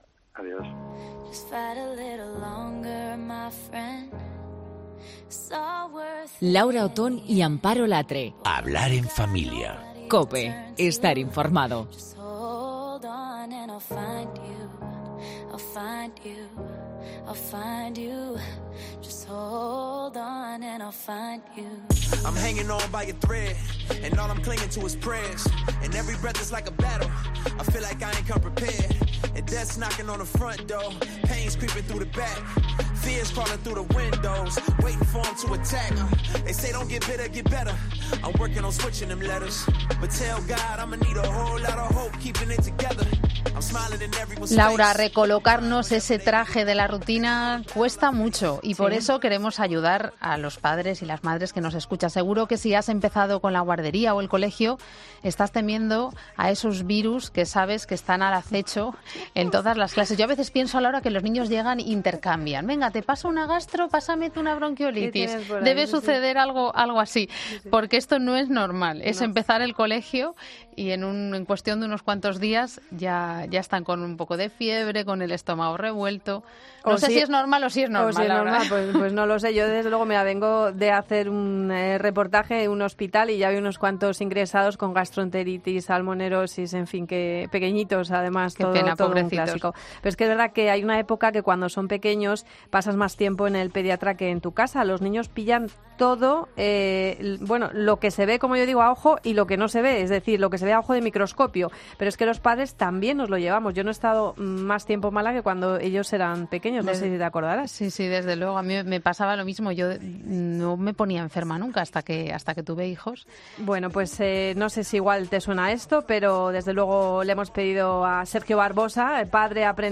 Aunque escuchar esta entrevista te puede cambiar los esquemas porque resulta que los niños ¡tienen más defensas que nosotros!.